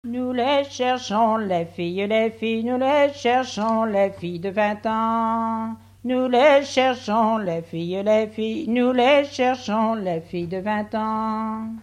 refrain de conscrits
Chants brefs - Conscription
Pièce musicale inédite